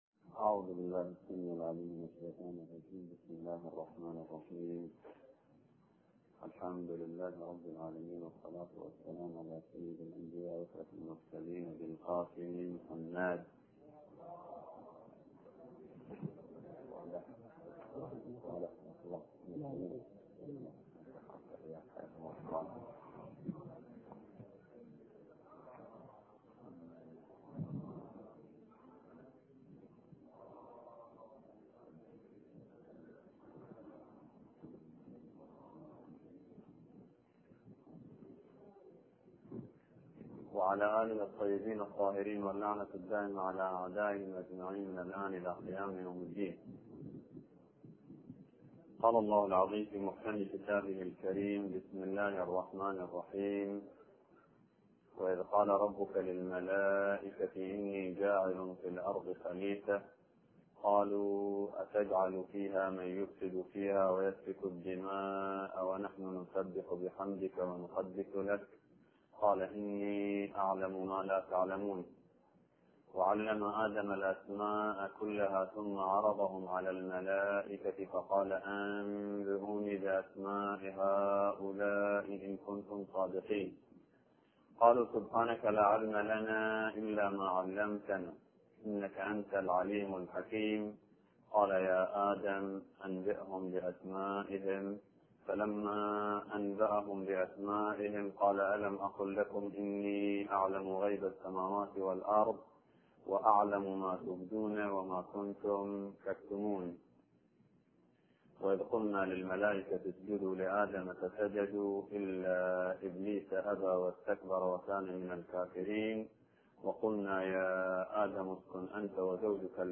ملف صوتی العلم بناء و حضارة - 1 بصوت الشيخ نمر باقر النمر
كلمة الجمعة